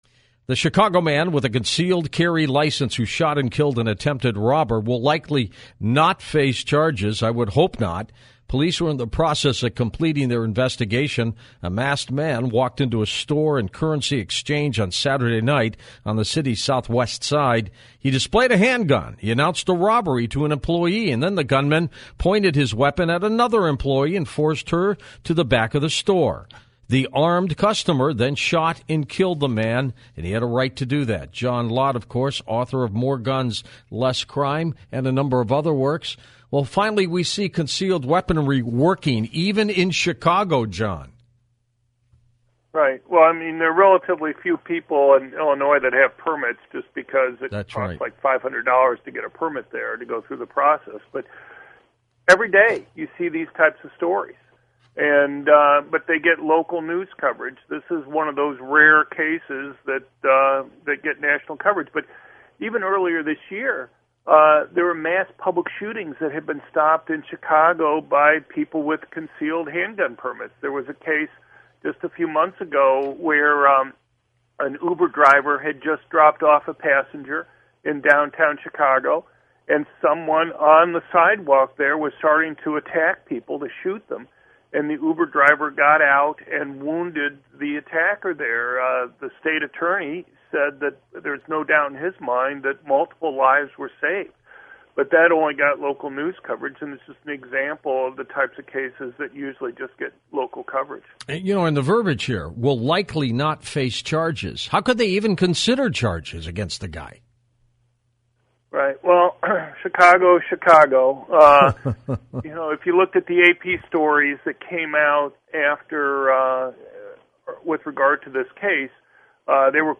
media appearance
John Lott talked to George Noory about a concealed handgun permit holder stopping an armed robbery in Chicago and other similar cases during October (Tuesday, November 3, 2015 from 1:10 to 1:13 AM).